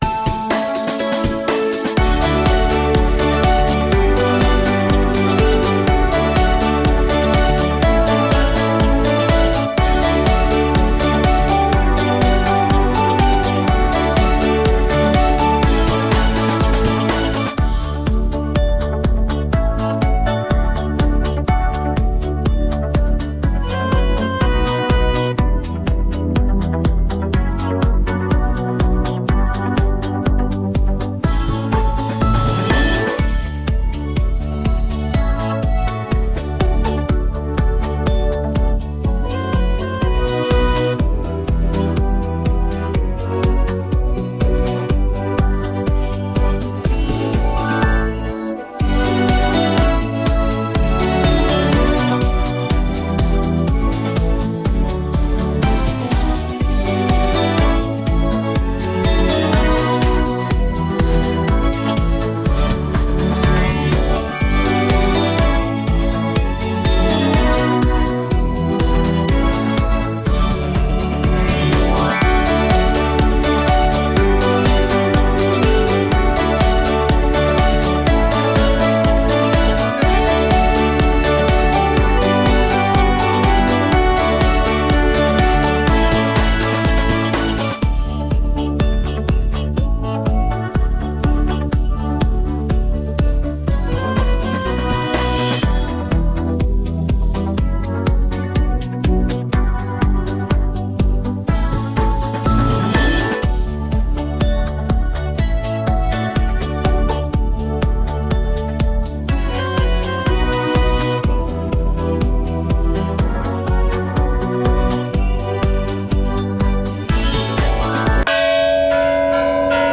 Скачать минус детской песни